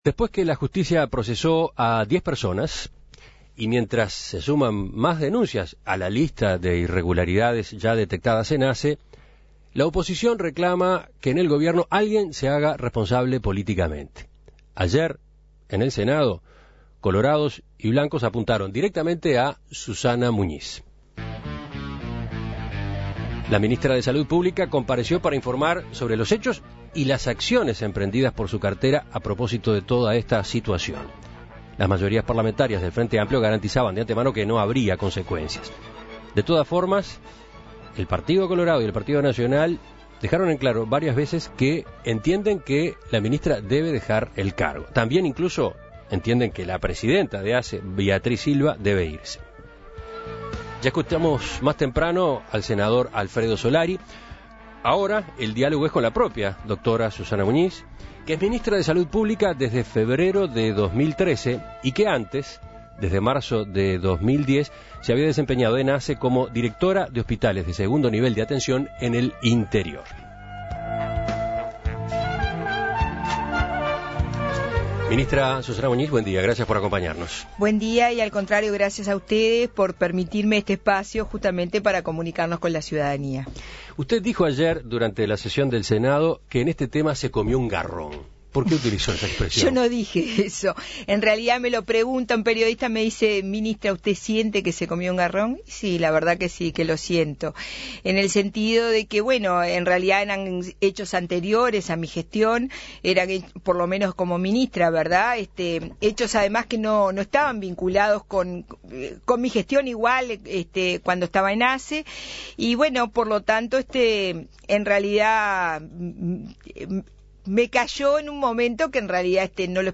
Desde la oposición entienden que sus respuestas no fueron suficientes y reclaman su renuncia. En Perspectiva consultó a la ministra sobre el tema.